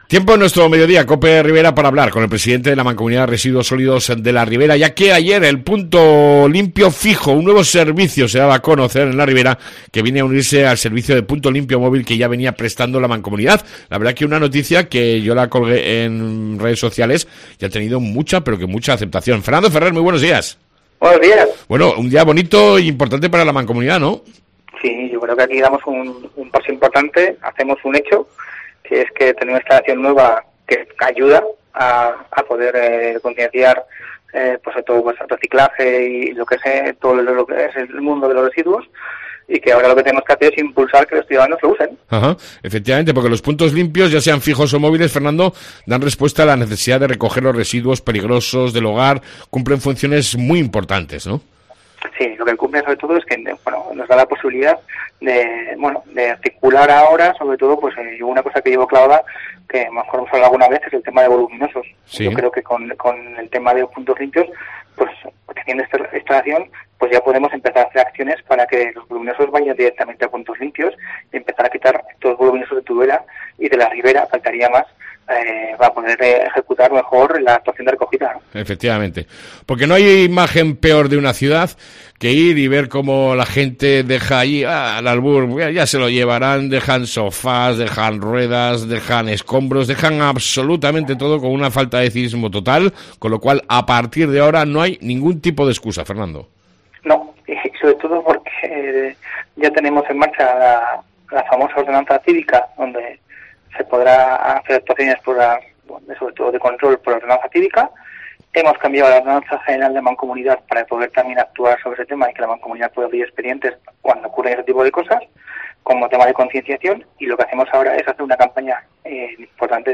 AUDIO: Entrevista con el Presidente de la Mancomunidad de residuos Fernando ferrer